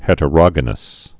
(hĕtə-rŏgə-nəs)